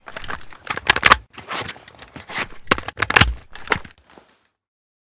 aks74_reload.ogg